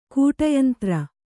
♪ kūṭa yantra